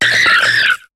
Cri de Négapi dans Pokémon HOME.